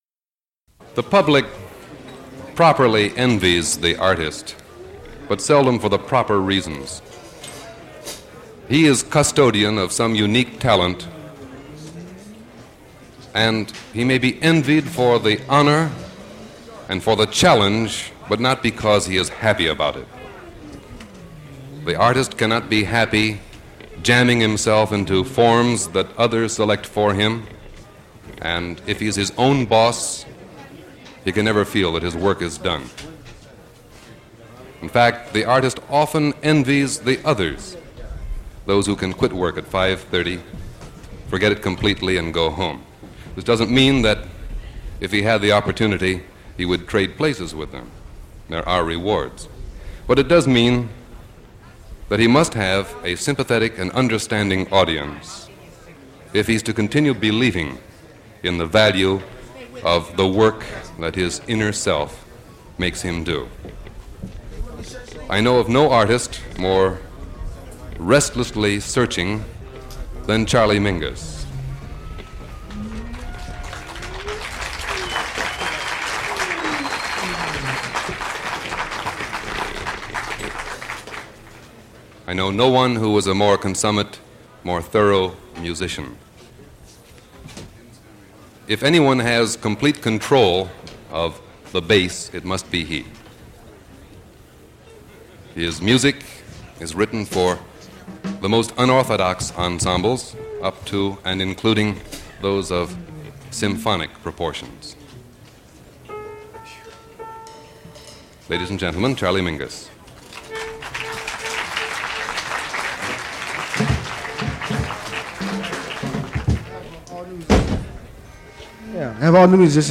Newport Jazz Festival 1959
In this case the Avant-Garde in Jazz.